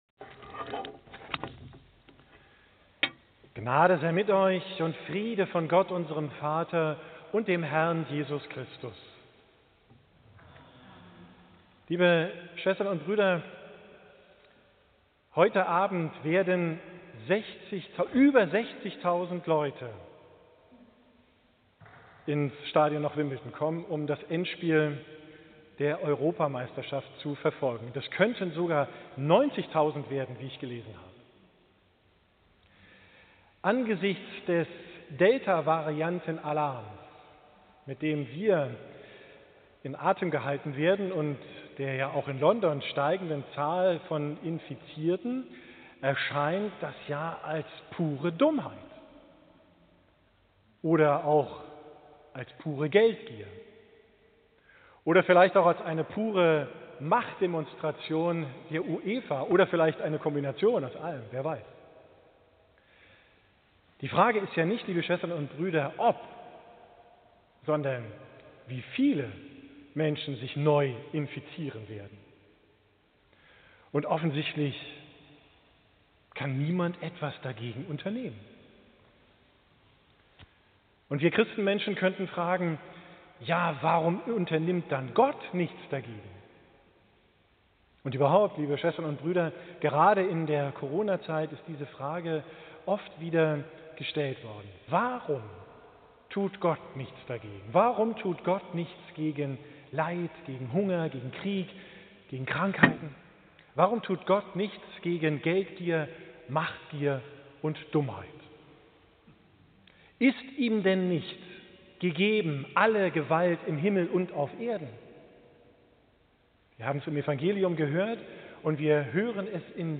Predigt vom 6.